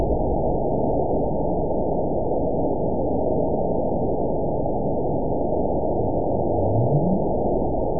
event 920435 date 03/25/24 time 00:21:14 GMT (1 year, 7 months ago) score 9.65 location TSS-AB02 detected by nrw target species NRW annotations +NRW Spectrogram: Frequency (kHz) vs. Time (s) audio not available .wav